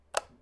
Kenney's Sound Pack/UI Audio • Directory Lister
switch21.ogg